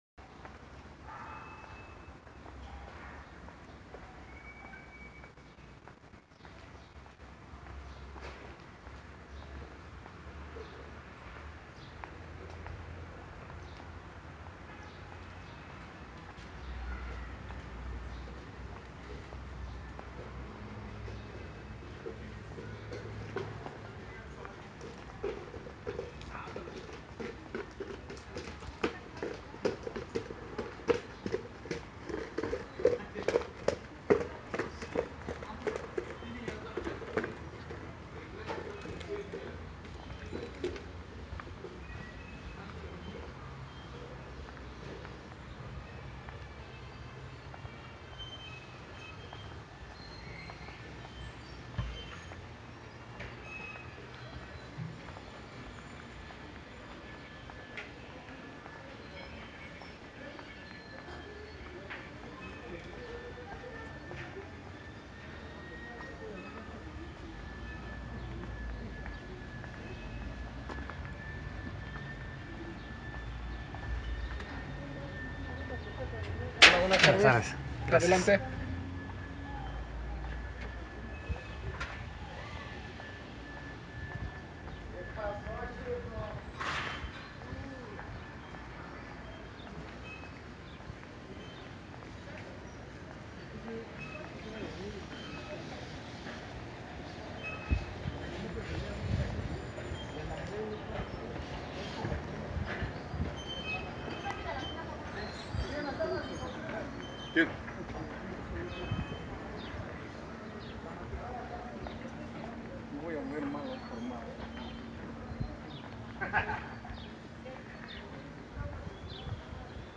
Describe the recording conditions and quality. A day in a street market Mexico City lockdown sounds